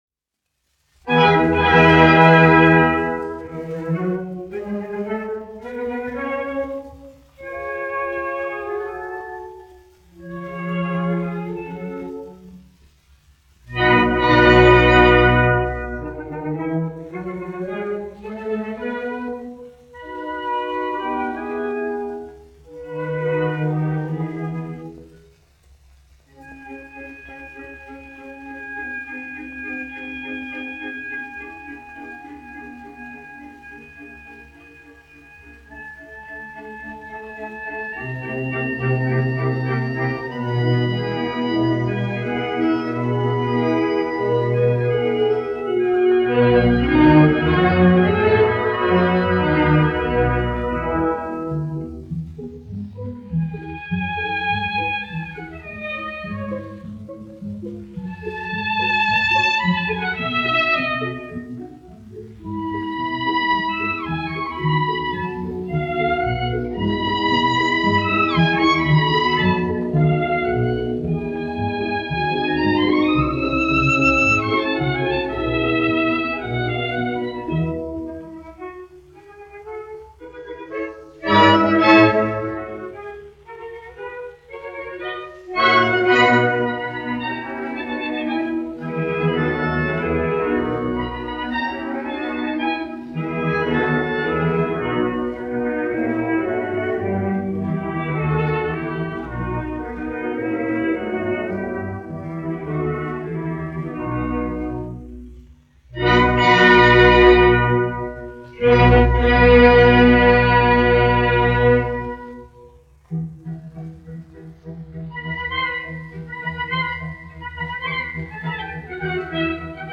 Staatskapelle Berlin, izpildītājs
1 skpl. : analogs, 78 apgr/min, mono ; 25 cm
Operas--Fragmenti
Uvertīras